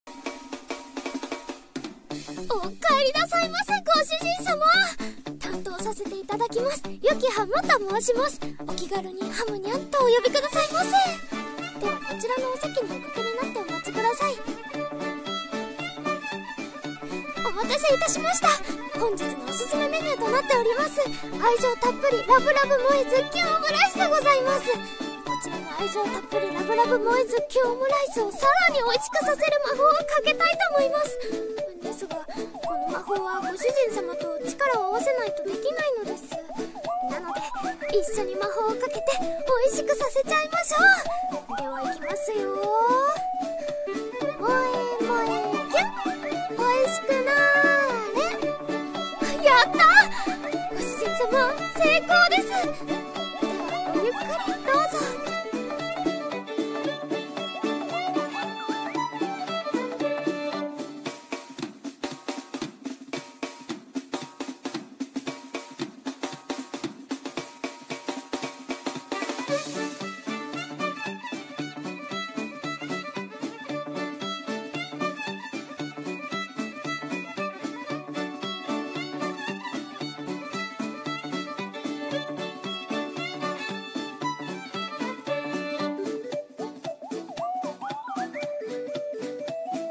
【声劇朗読】